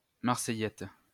Marseillette (French pronunciation: [maʁsɛjɛt]